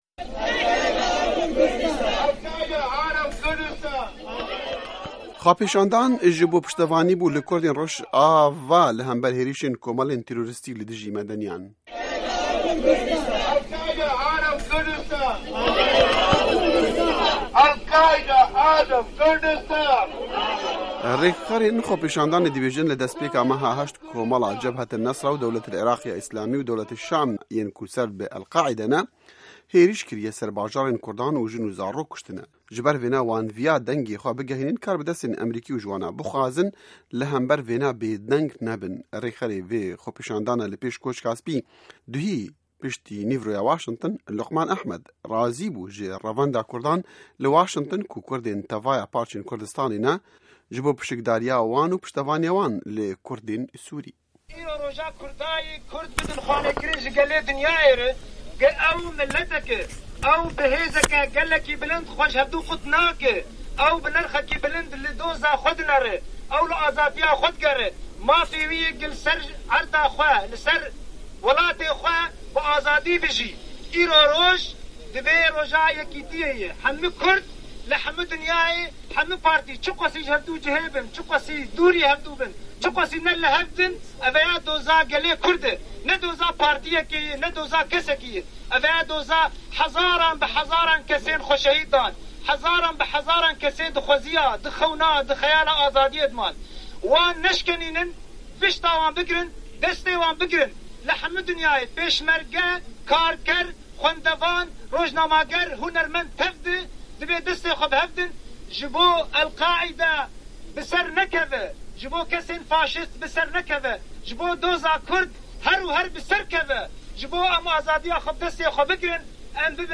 Dengê Amerîka li wir bû û ev raport amadekirîye.